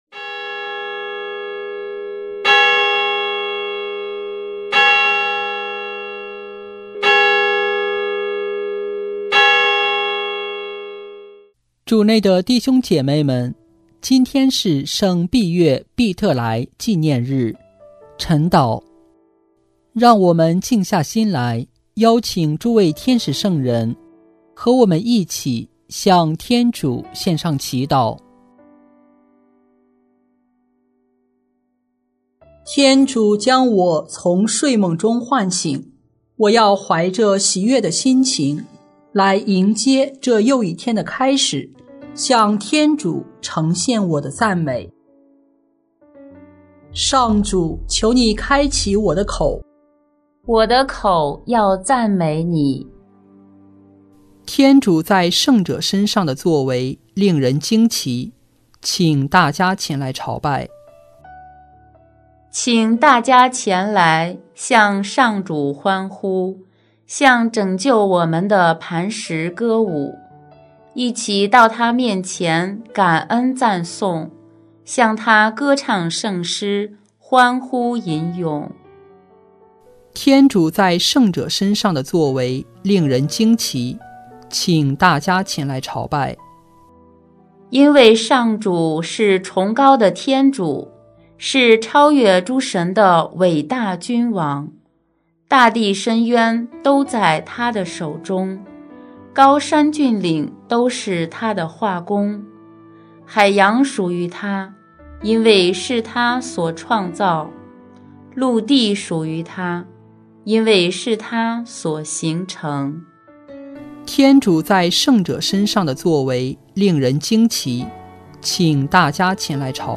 【每日礼赞】|9月23日圣碧岳·庇特来纪念晨祷（第一周周二）